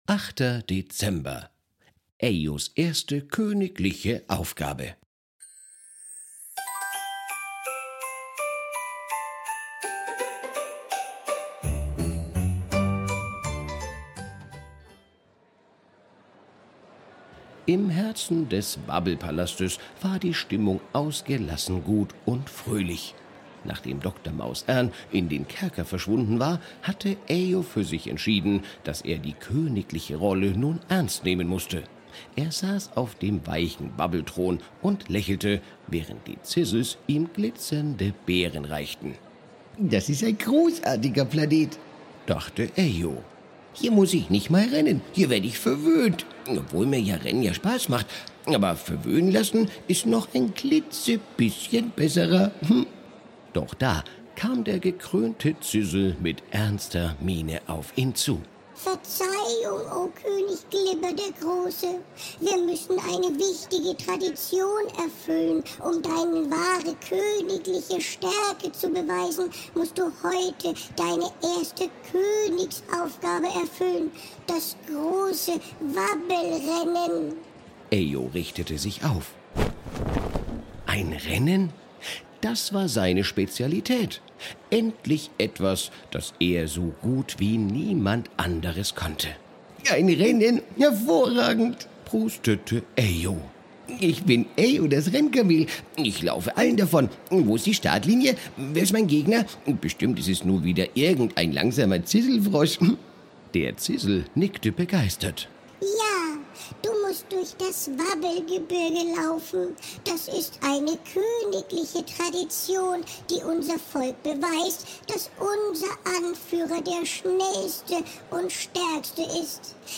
Ein Kinder Hörspiel Adventskalender